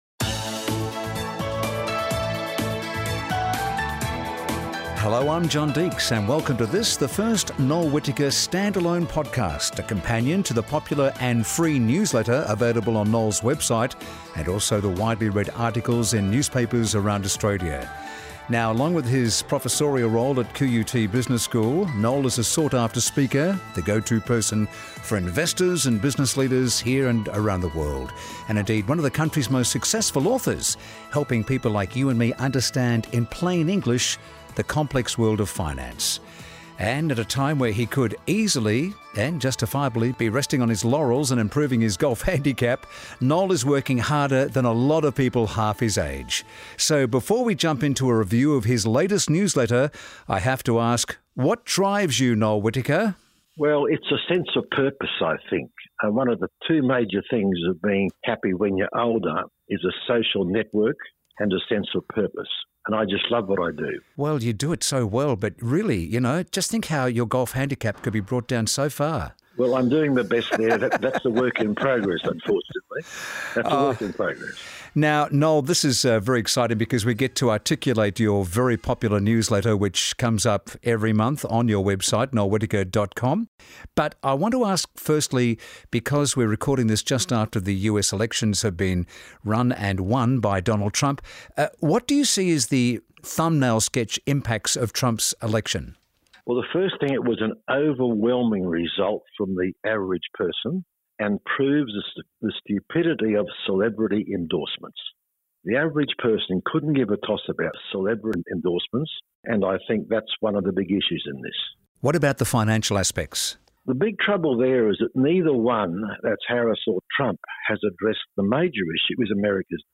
Instead of just reading the newsletter, renowned broadcaster John Deeks and I will be discussing everything covered in the issue.